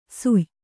♪ suy